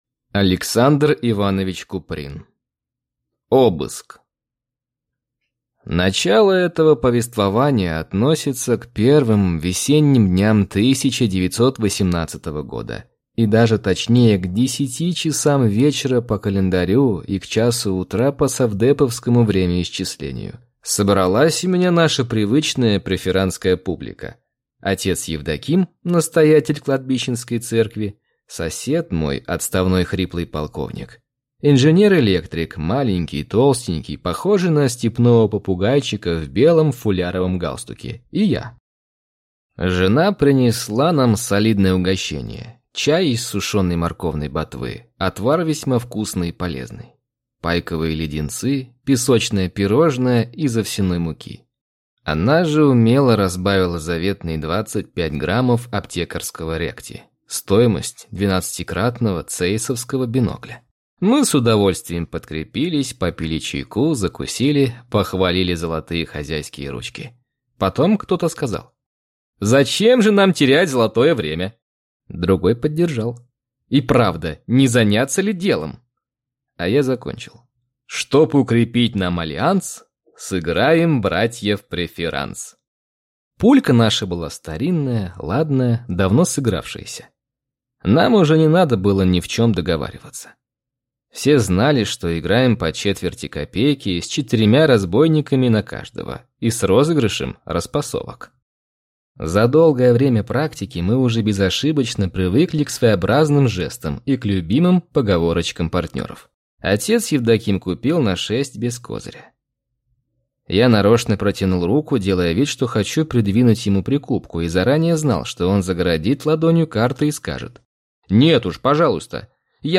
Аудиокнига Обыск | Библиотека аудиокниг